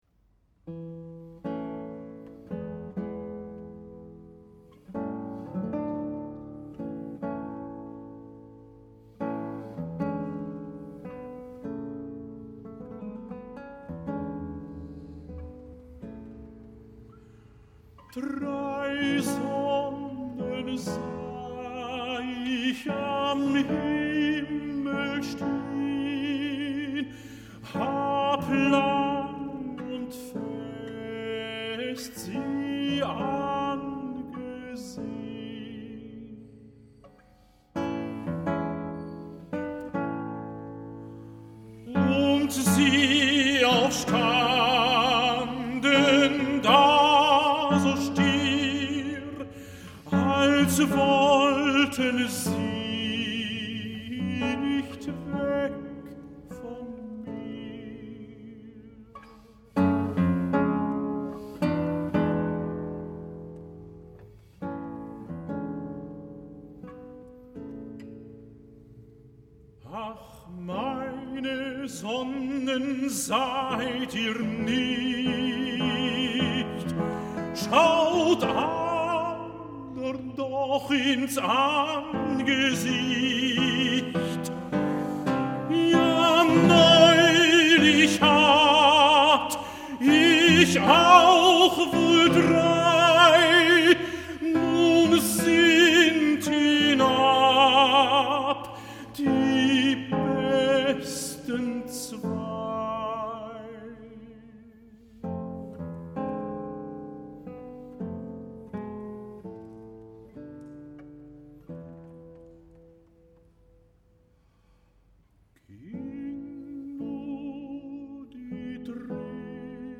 in concert
A really extraordinary live recording.“